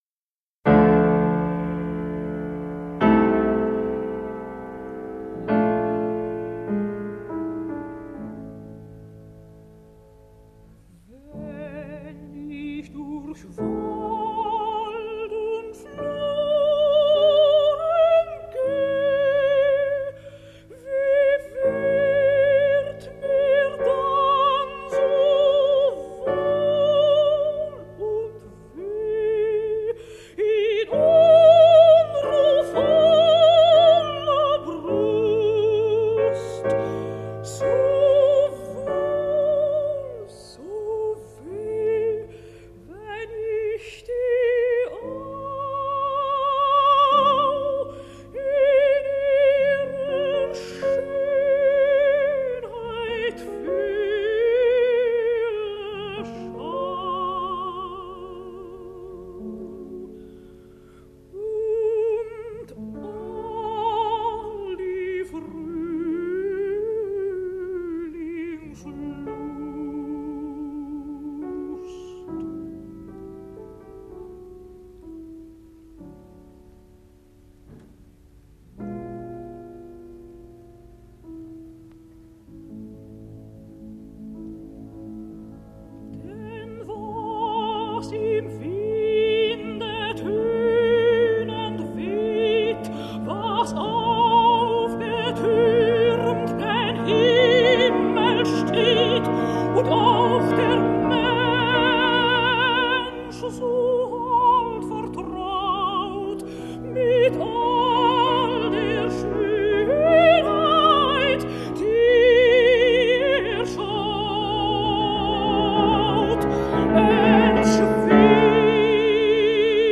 不想她是唱得清丽无比，还带点儿幽峭的意味。
当我们像 是感觉到钢琴声与人声参差而飞这种情景，谁能够不面对此种情景而生叹绝呢？